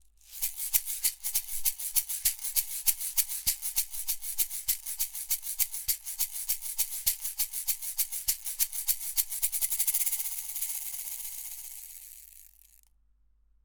Shaker B – Niere
Das Manley Reference Silver klingt hinreichend neutral für eine authentische Reproduktion der Schallquelle, gleichzeitig aber im besten Sinne warm und brillant nach Röhrenart.
manley_reference_silver_test__shaker_b__niere_flat.mp3